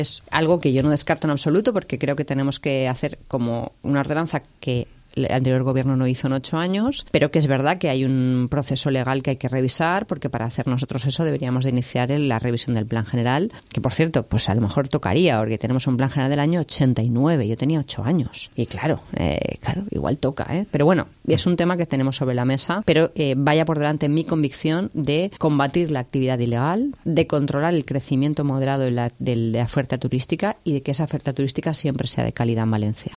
La alcaldesa María José Catalá, en una entrevista concedida a COPE València, asegura que "debería modificarse el PGOU, que tampoco es mala idea porque lleva sin tocar desde el 89"